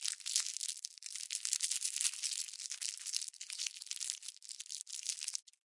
糖果袋的皱褶
描述：这是一个塑料m＆amp; m包褶皱的声音。
标签： 塑料 糖果 包装 起皱
声道立体声